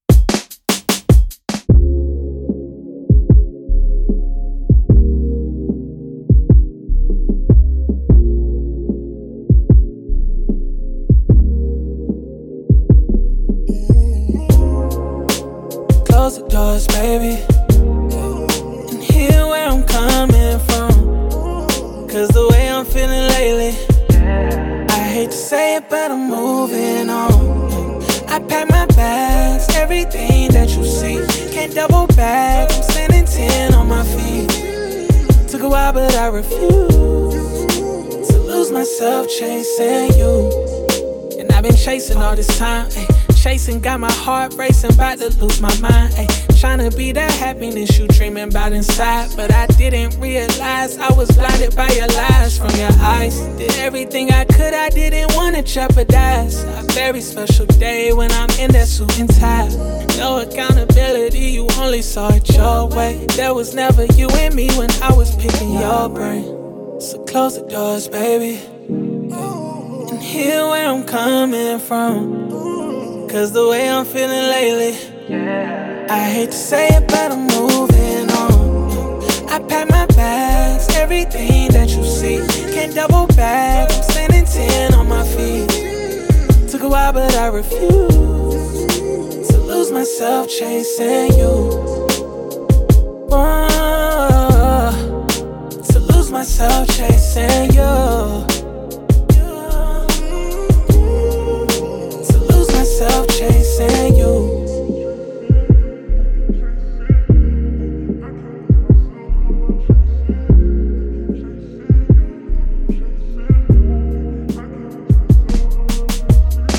R&B, Soul
F Min